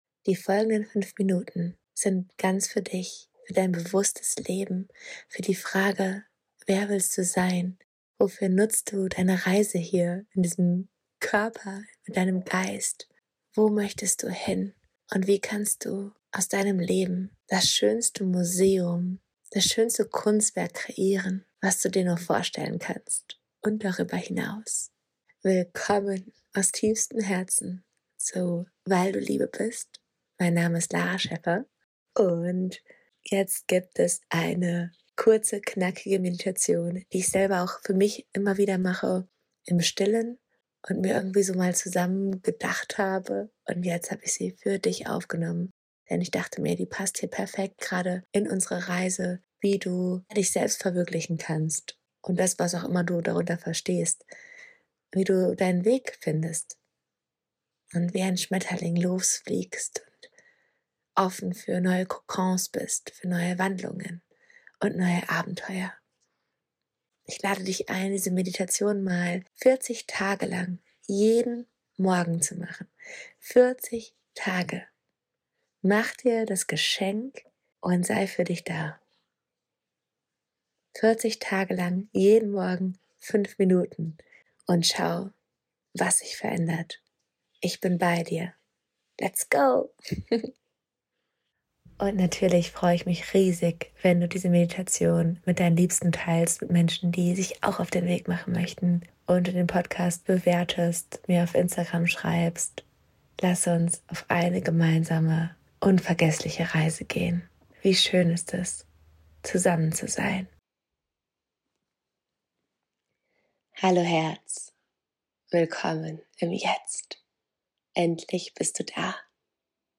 Eine kraftvolle Meditation - kurz und knackig für jeden Tag